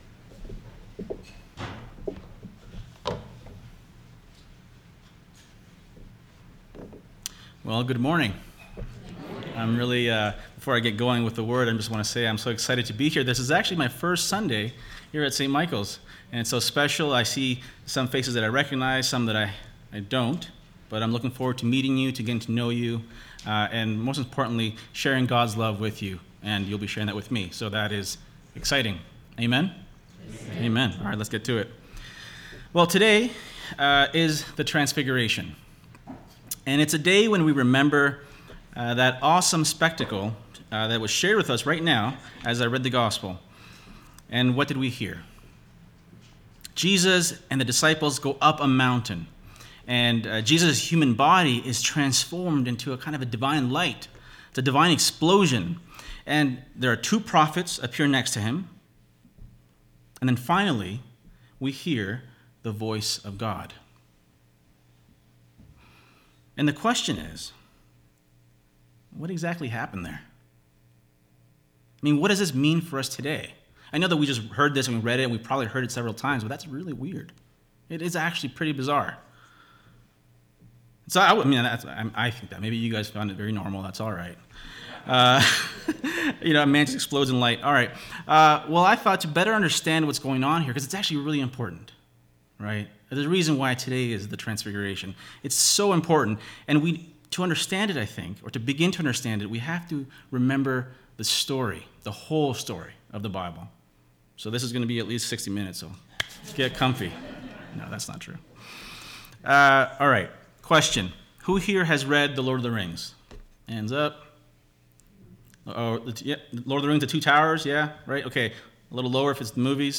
Sermons | St. Michael Anglican Church